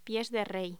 Locución: Pies de rey
voz